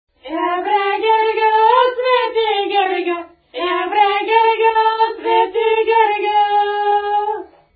музикална класификация Песен
размер Девет осми
фактура Едногласна
начин на изпълнение Двойка (без отпяване 1,1)
битова функция Гергьовско хоро
фолклорна област Югоизточна България (Източна Тракия с Подбалкана и Средна гора)
място на записа Одринци
начин на записване Магнетофонна лента